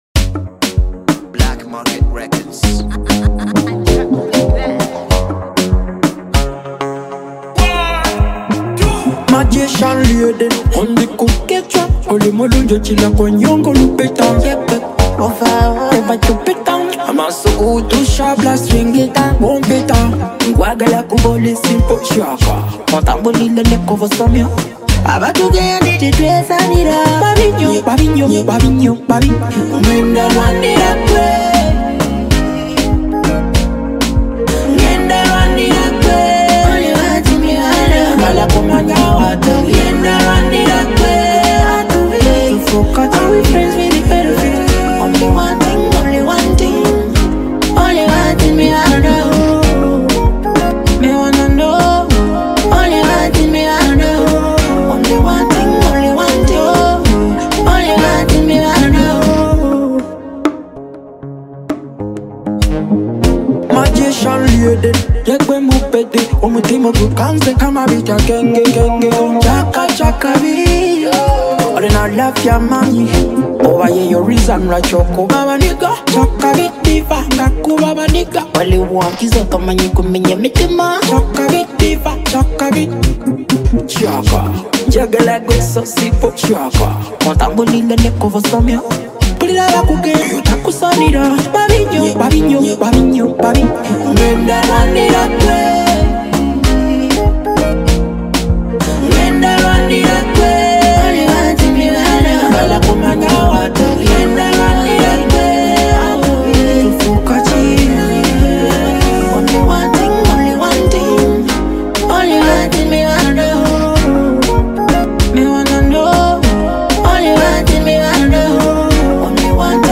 With his signature vocals and sleek production